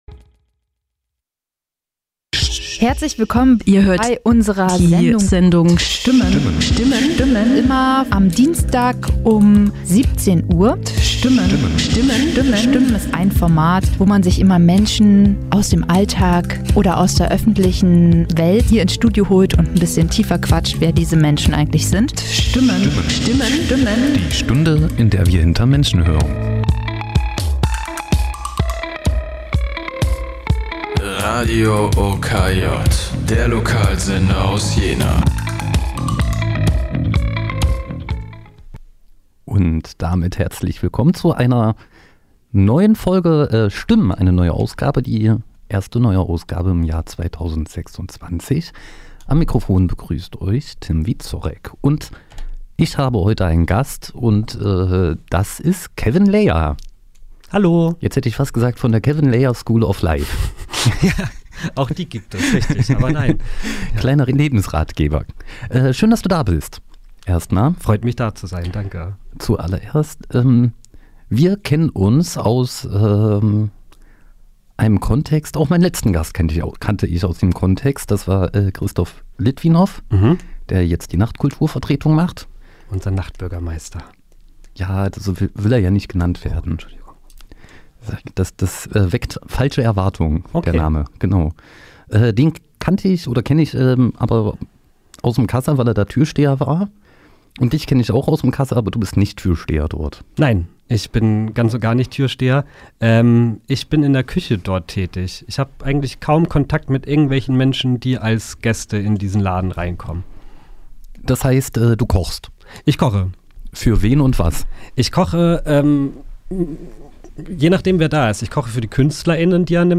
Ein Gespräch über die Facetten von Jena, von Kohl und was eigentlich Interviewsituationen ausmacht.